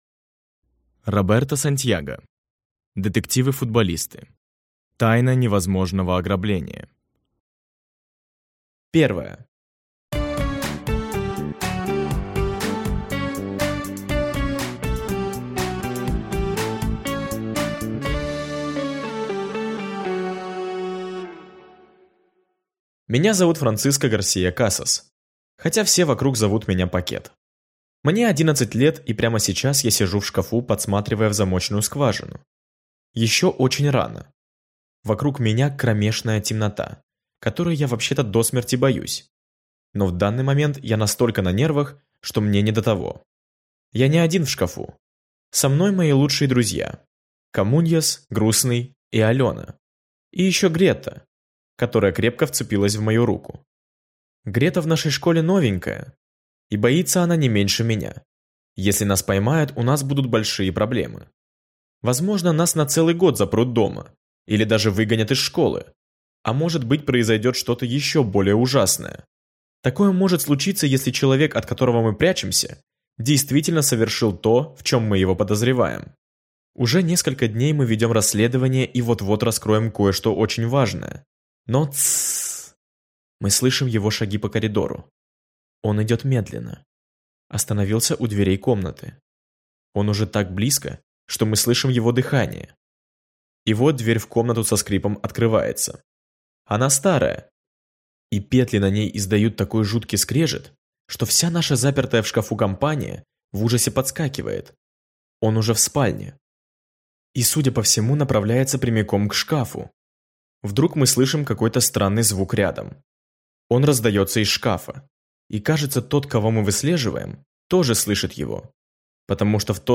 Аудиокнига Детективы-футболисты. Тайна невозможного ограбления | Библиотека аудиокниг